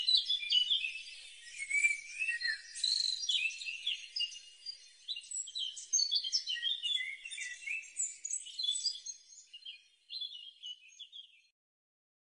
暗绿绣眼鸟叫声